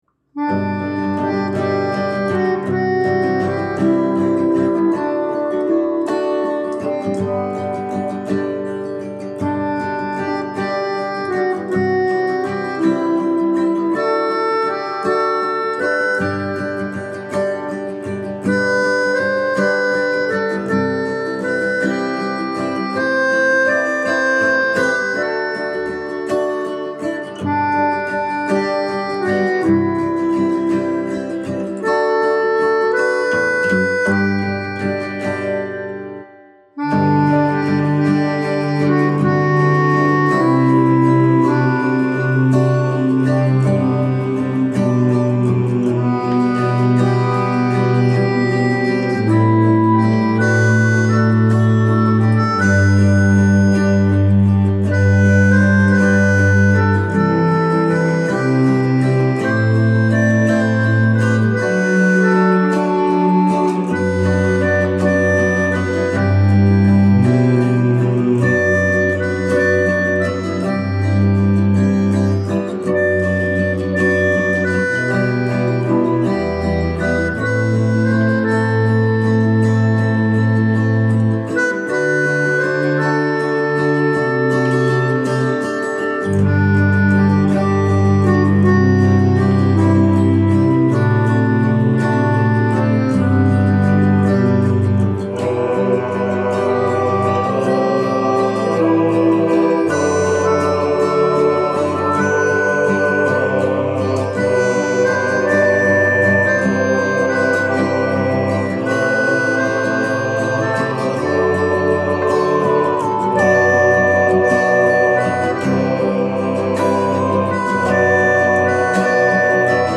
Soundtrack
Ist auch so schön; ich finde, ich habe einen Sound gefunden – der Pathos ist gerade noch erträglich!